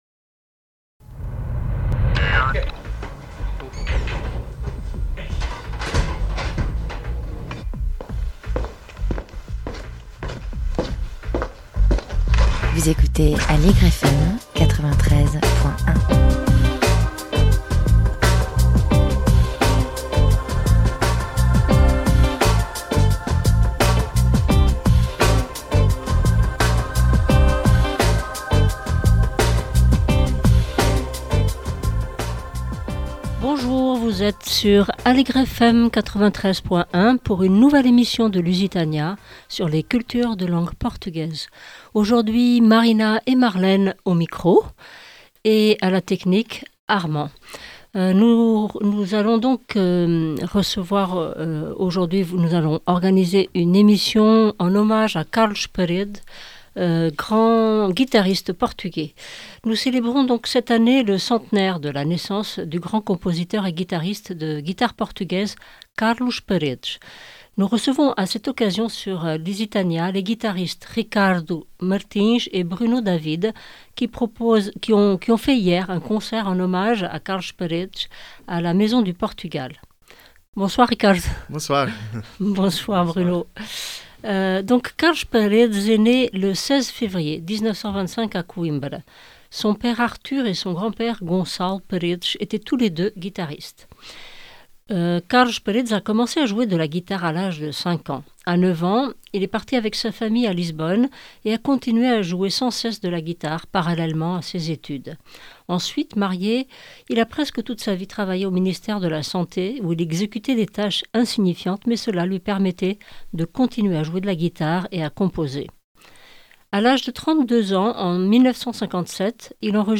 Ils nous ont interprété plusieurs titres de la composition de Carlos Paredes et un de son grand-père.